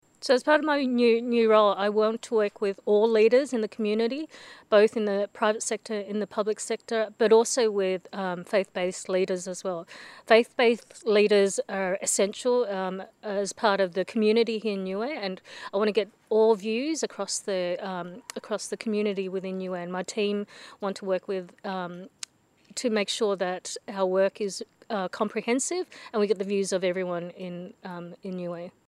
Speaking to BCN News, Phongkham shared on the importance of engaging with the various community leaders in both the public and private sector which includes faith based leaders.